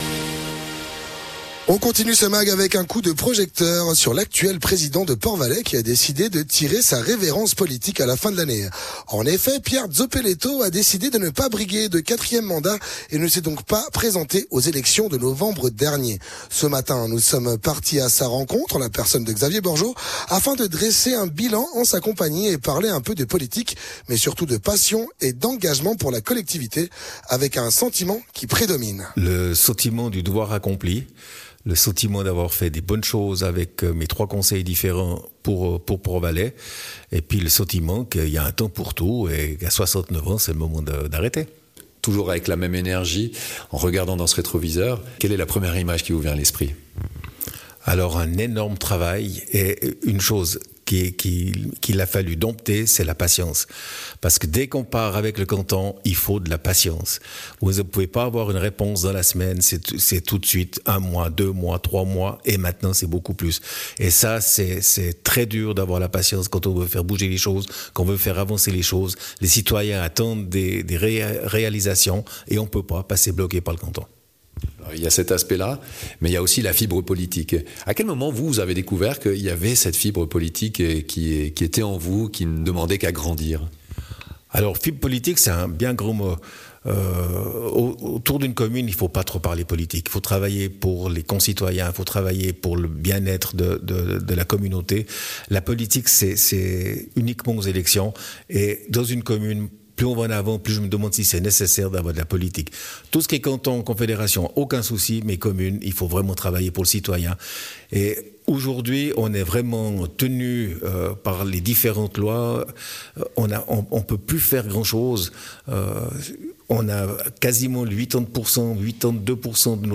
Intervenant(e) : Pierre Zoppelletto, Président de Port-Valais